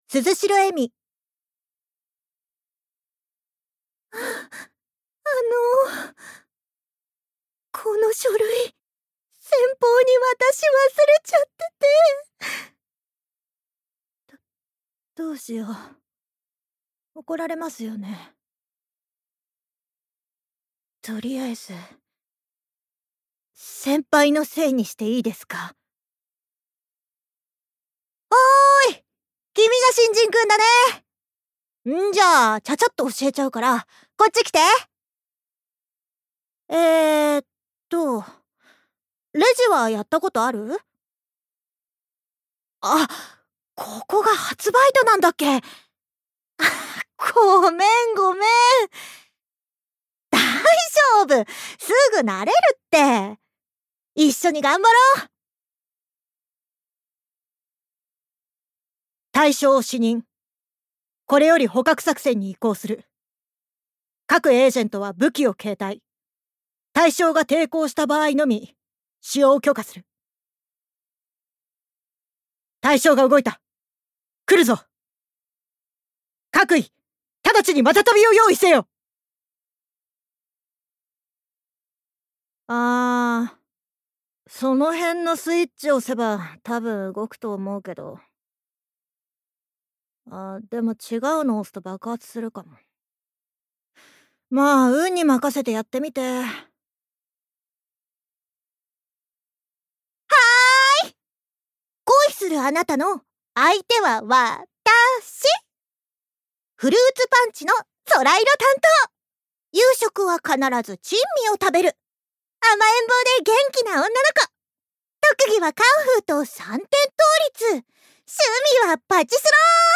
◆ボイスサンプル◆